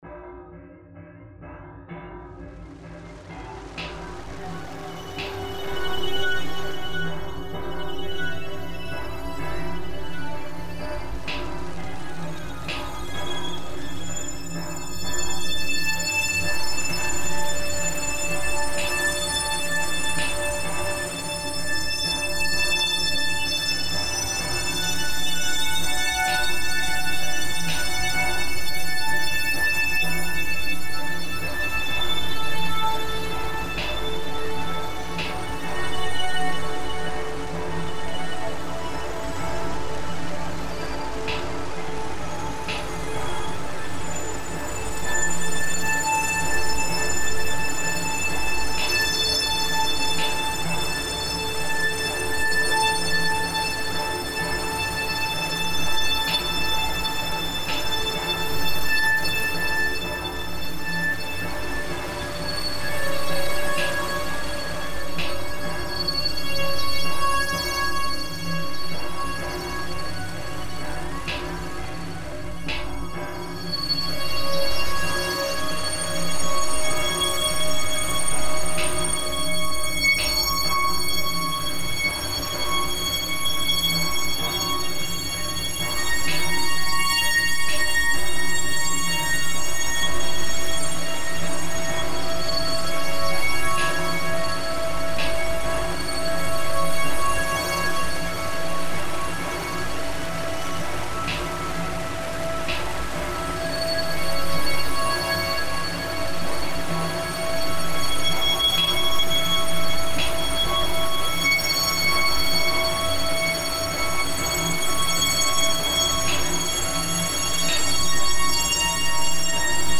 brighter and warmer stufff